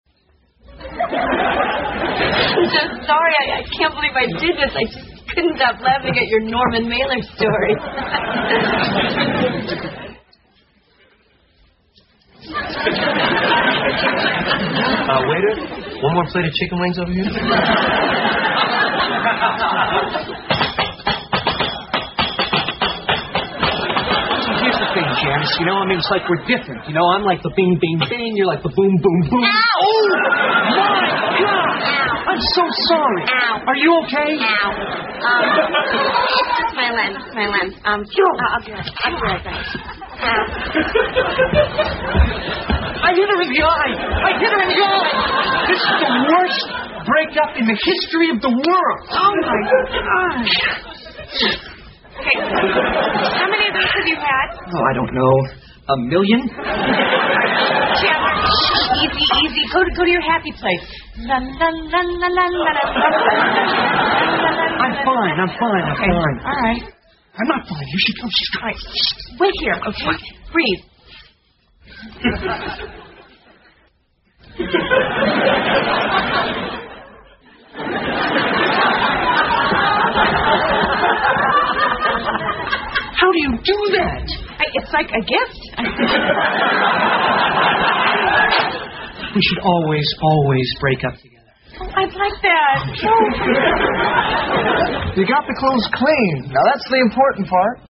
在线英语听力室老友记精校版第1季 第59期:洗衣服(12)的听力文件下载, 《老友记精校版》是美国乃至全世界最受欢迎的情景喜剧，一共拍摄了10季，以其幽默的对白和与现实生活的贴近吸引了无数的观众，精校版栏目搭配高音质音频与同步双语字幕，是练习提升英语听力水平，积累英语知识的好帮手。